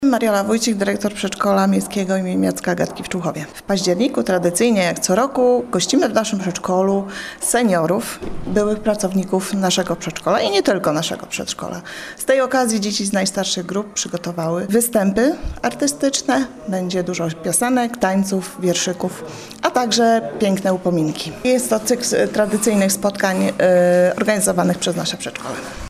Jak co roku podopieczni Przedszkola Miejskiego im. Jacka i Agatki w Człuchowie przygotowały okolicznościową akademię z okazji Dnia Nauczyciela.